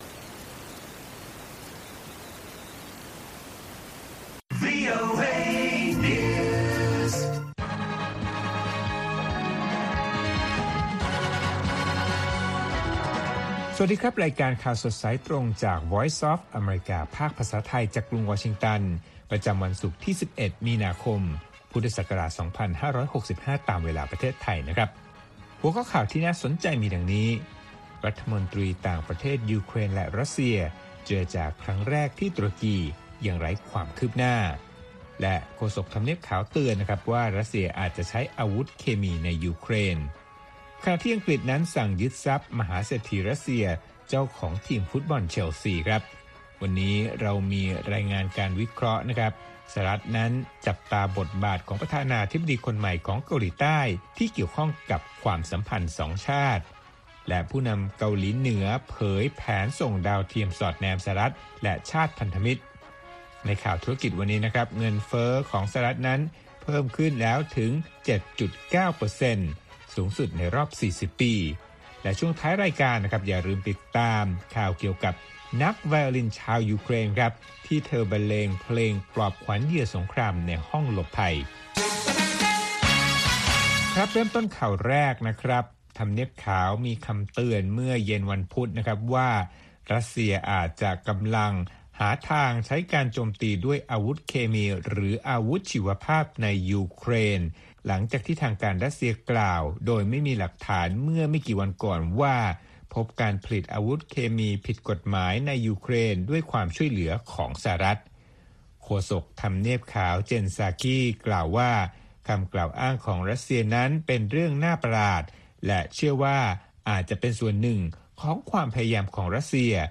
ข่าวสดสายตรงจากวีโอเอ ภาคภาษาไทย ประจำวันศุกร์ที่ 11 มีนาคม 2565 ตามเวลาประเทศไทย